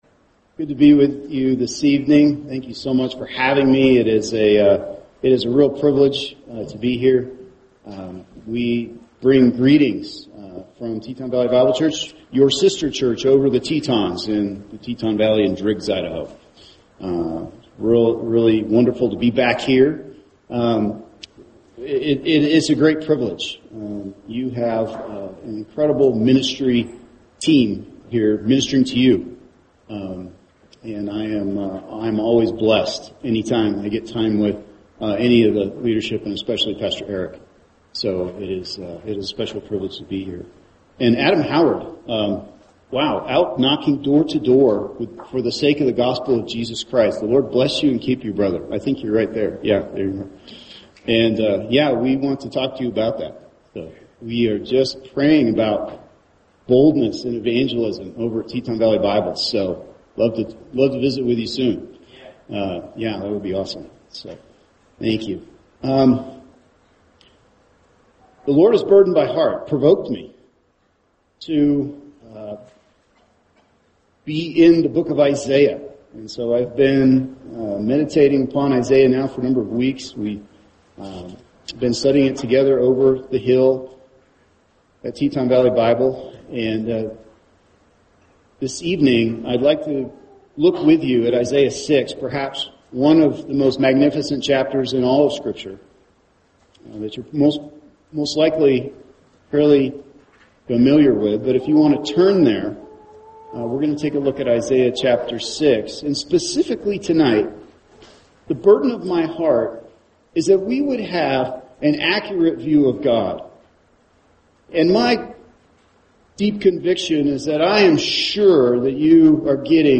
[sermon] Isaiah 6:1-6 – A High View of God | Cornerstone Church - Jackson Hole